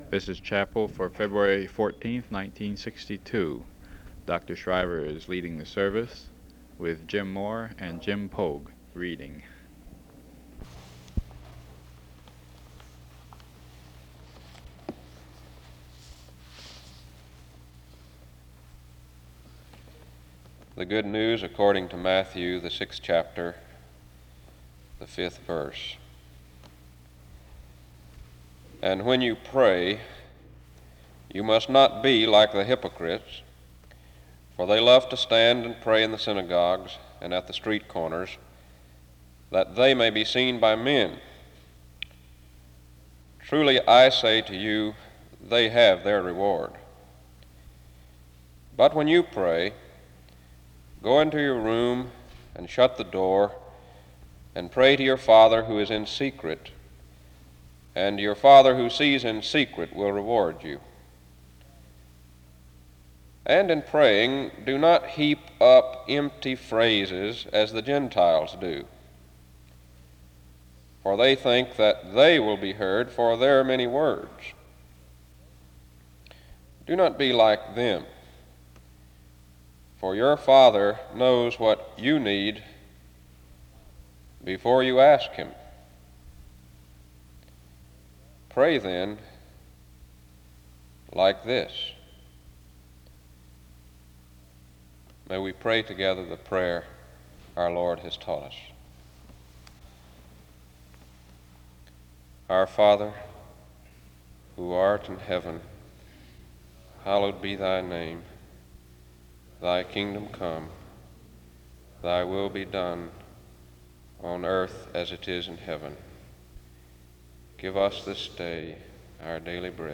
SEBTS Chapel
The service begins with a reading of Matthew 6:5-9 from 0:20-1:33. The service prays the Lord’s Prayer from 1:35-2:22.
A special reading on prayer takes place from 9:27-14:59.